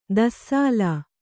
♪ dassāla